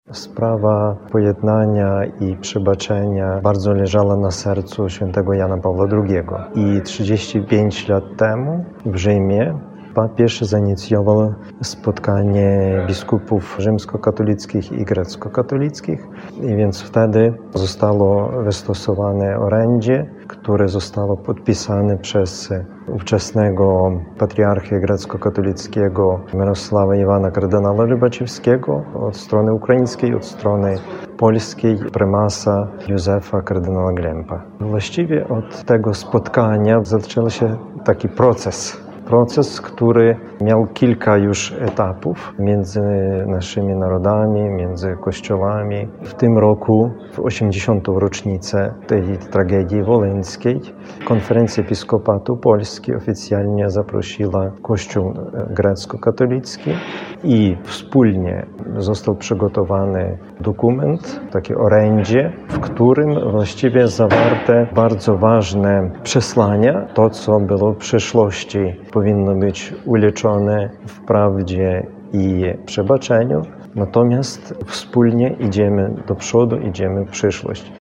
-Czy i jak możliwe jest pojednanie narodów po tylu latach od tragicznych wydarzeń? – zastanawia się Biskup Teodor Martyniuk MSU, biskup katolicki Ukraińskiego Kościoła Greckokatolickiego, przybliżając ideę pojednania za pontyfikatu Ojca Świętego Jana Pawła II.
01_biskup-greckokatolicki-wolyn.mp3